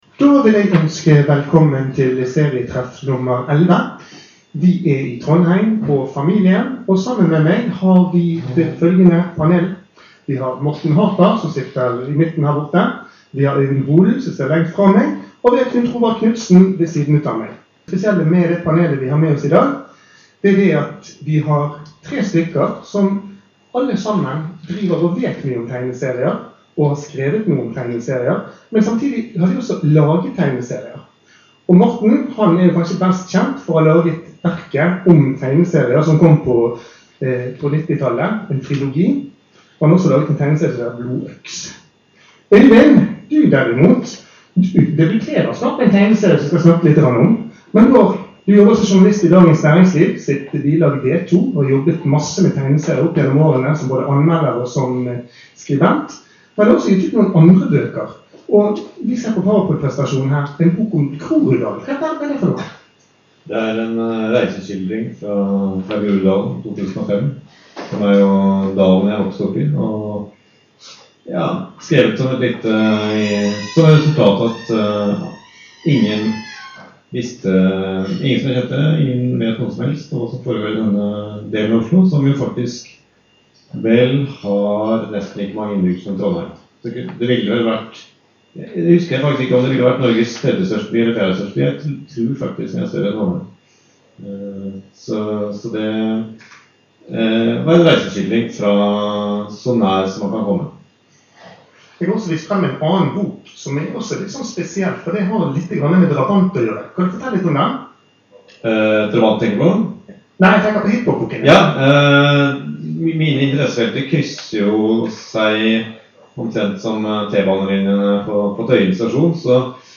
Serietreff nr. 11 gikk av stabelen i Trondheim 14. april. Her er podkasten fra arrangementet, der litteratur om tegneserier og Sproingprisnominasjonene stod i sentrum.
Paneldebatt del 1